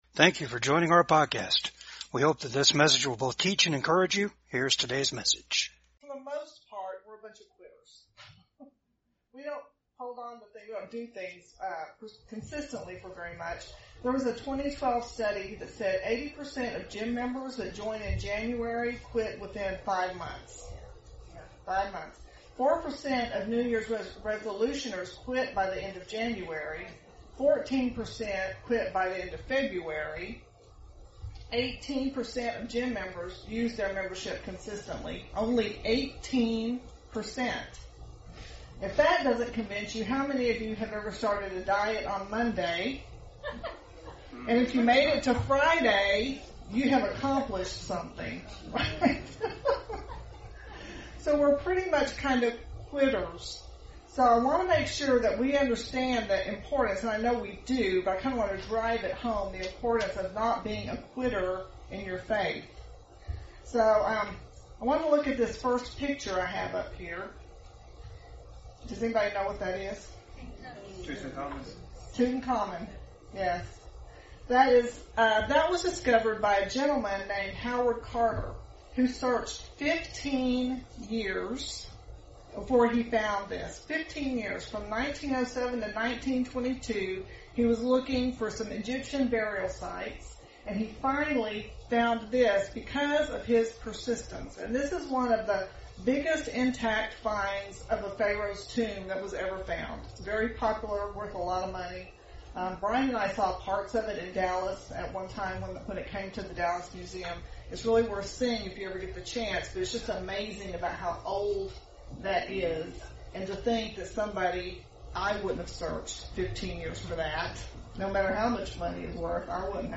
James 4:3 Service Type: VCAG WEDNESDAY SERVICE ASK WITH RIGHT MOTIVES.